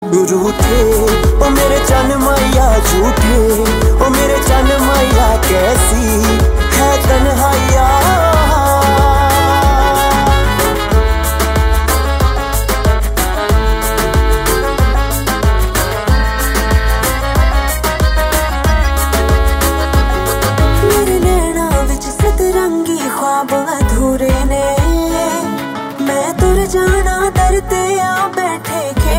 Punjabi Songs
• Simple and Lofi sound
• Crisp and clear sound